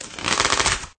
tear_longer_rippling.ogg